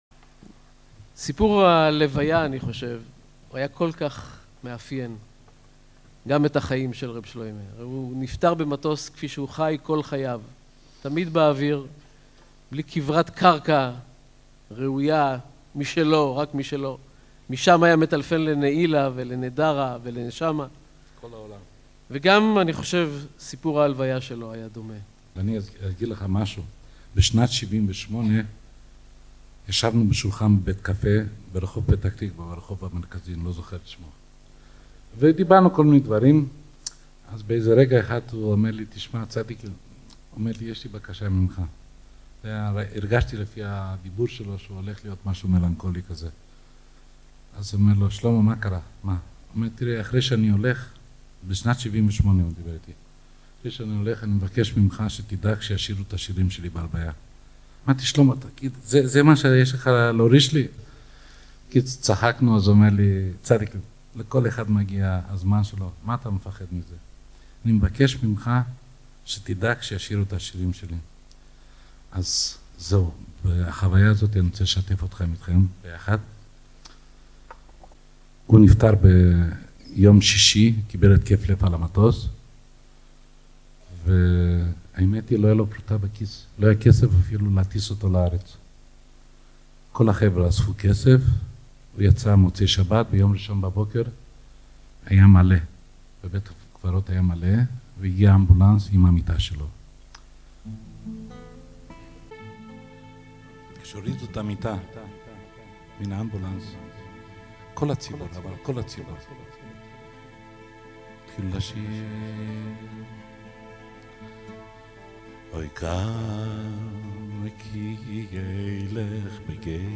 ובהקלטה זו תשמעו מחרוזת שירים קצרה מהלוויתו של רבי שלמה קרליבך.